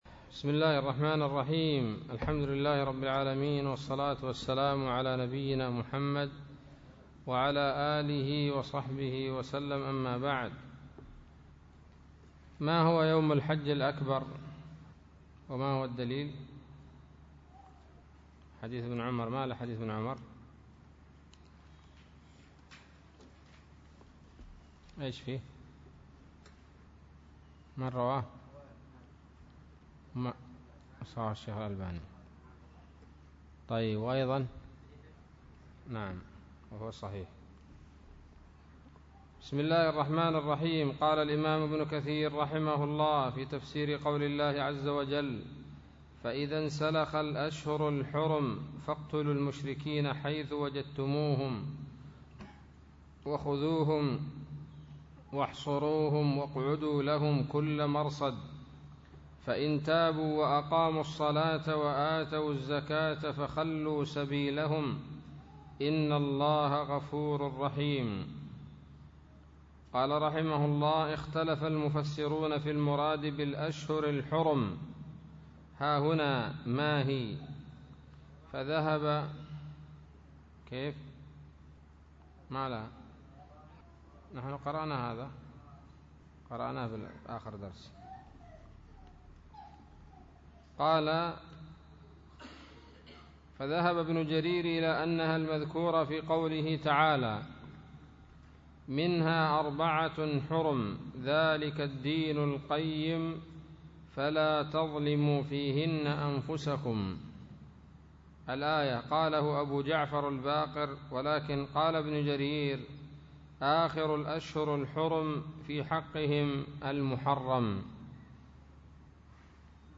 الدرس الخامس من سورة التوبة من تفسير ابن كثير رحمه الله تعالى